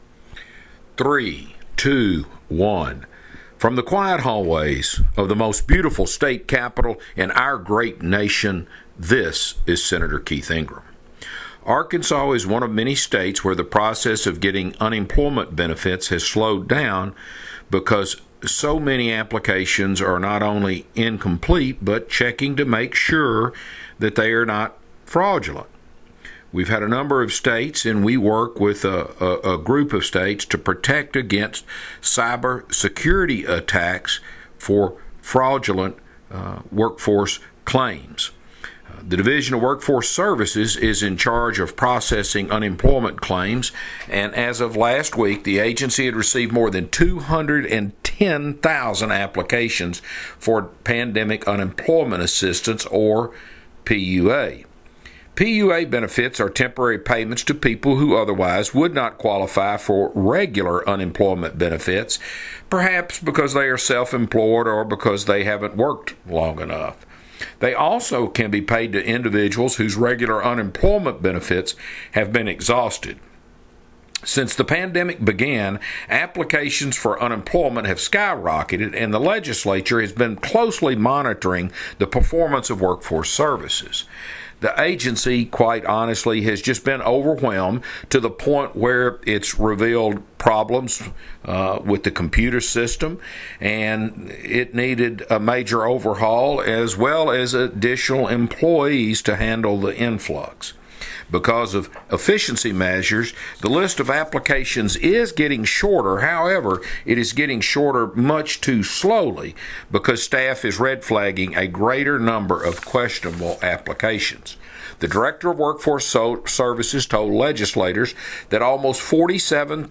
Weekly Address – September 18, 2020 | 2020-09-18T18:09:05.859Z | Sen.